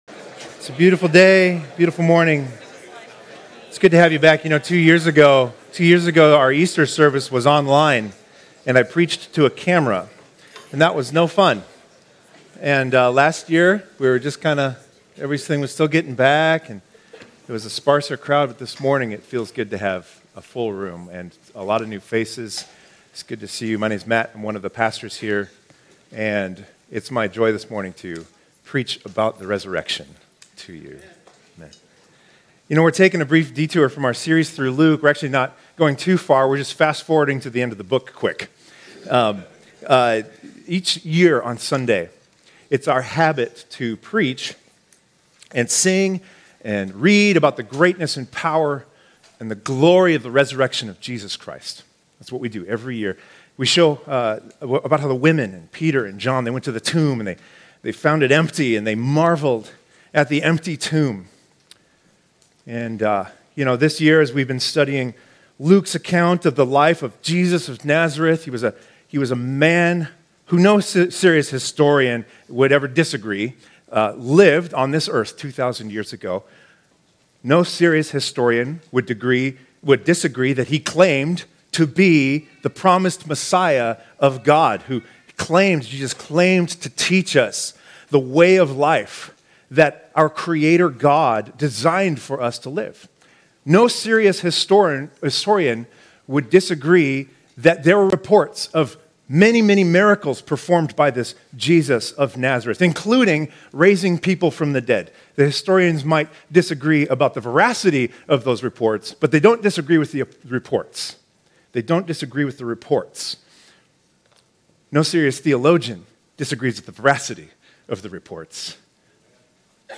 2022 Stay up to date with “ Stonebrook Church Sermons Podcast ”